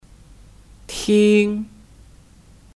听 Tīng (Kata kerja): Mendengar Contoh kalimat:你要听妈妈的话 Nǐ yào tīng māma de huà (Kamu harus mendengar perkataan mama)